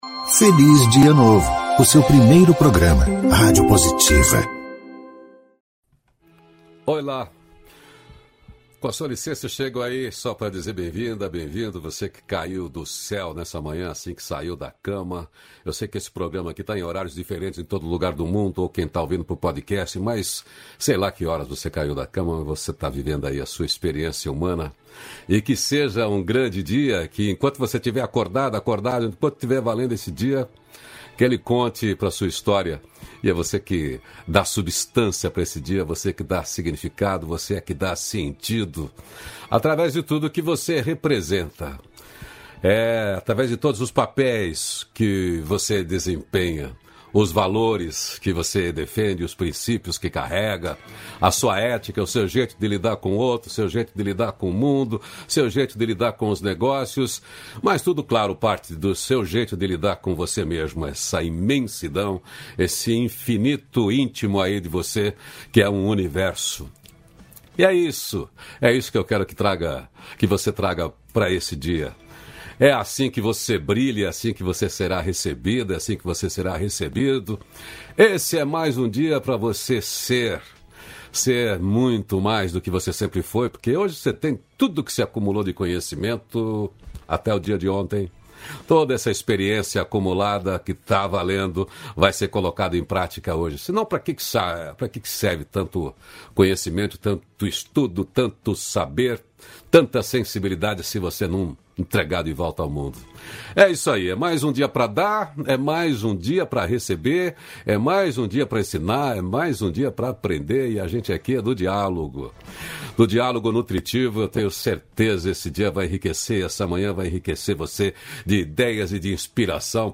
350-feliz-dia-novo-entrevista.mp3